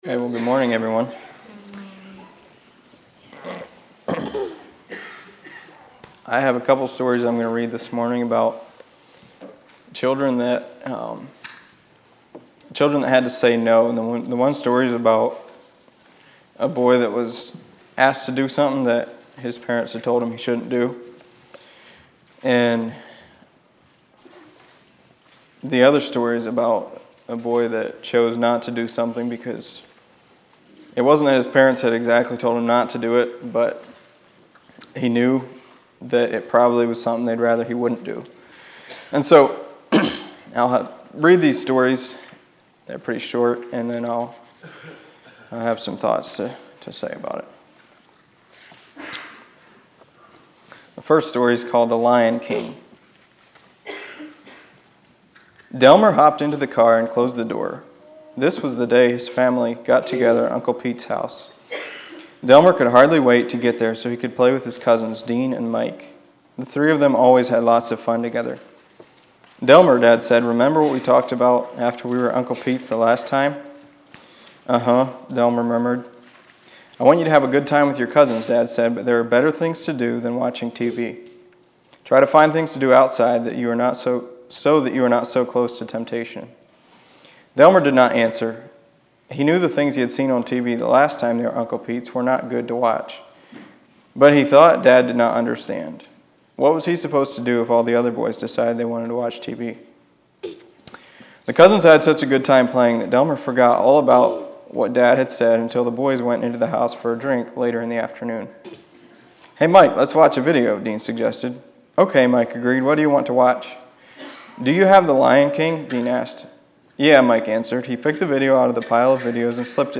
Children's Lessons